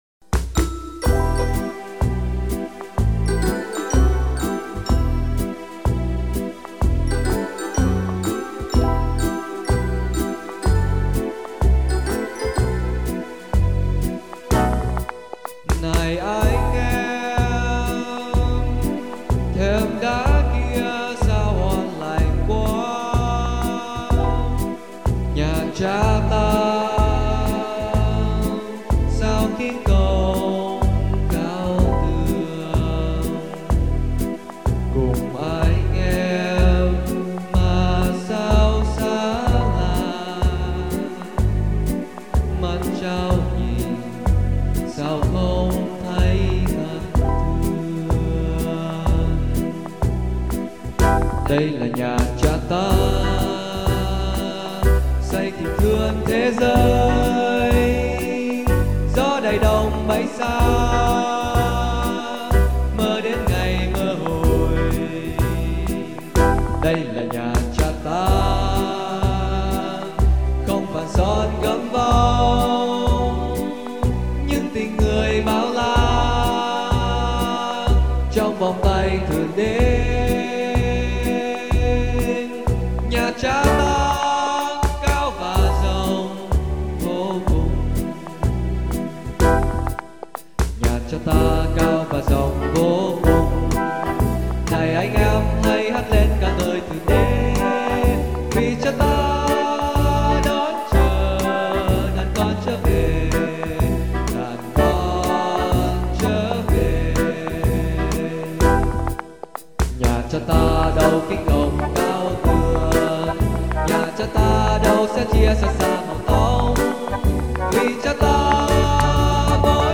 Nha-Cha-Ta-Vocal-Em-2016.mp3